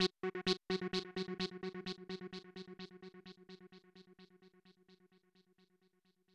TEC FX.wav